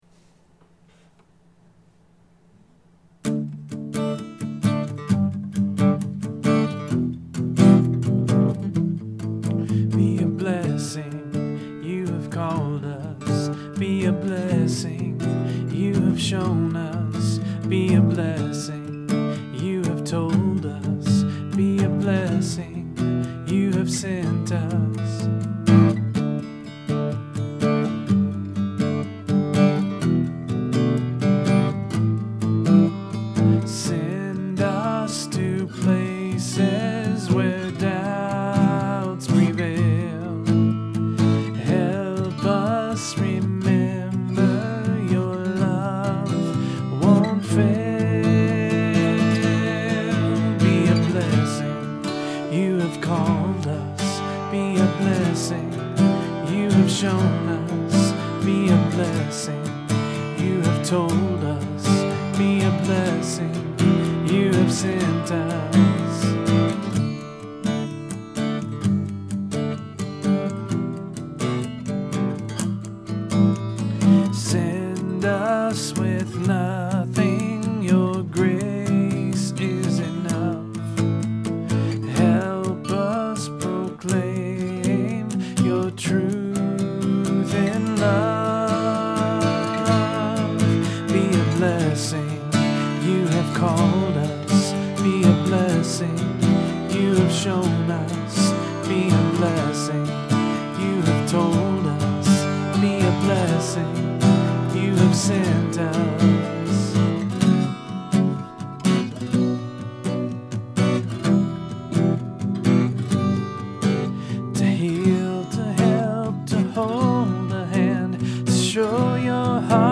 I wanted to write a sending song.